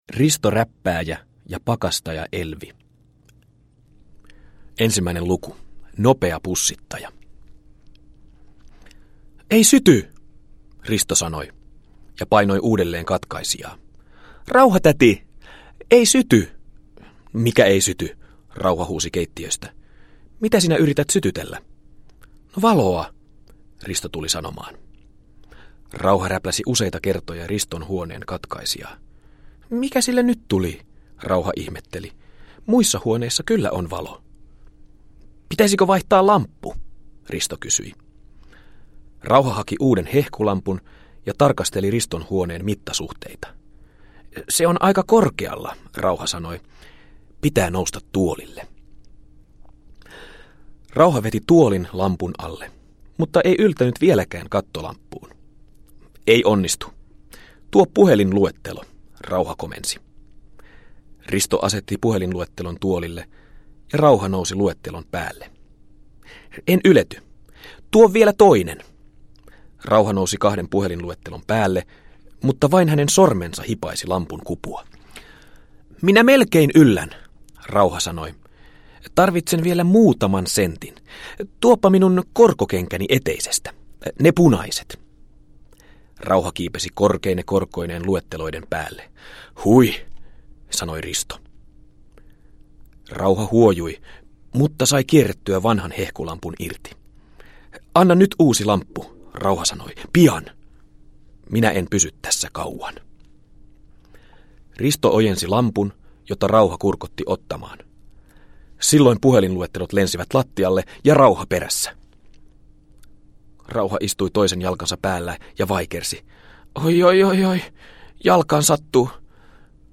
Risto Räppääjä ja pakastaja-Elvi – Ljudbok